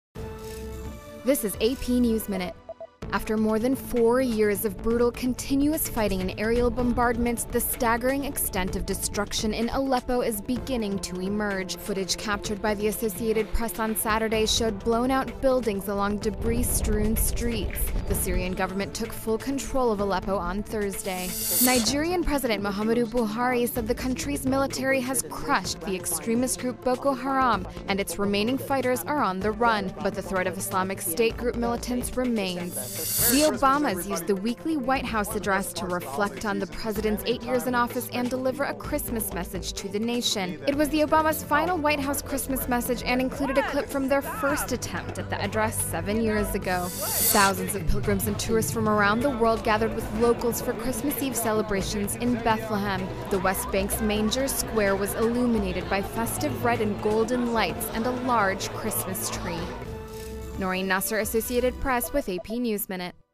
英语资讯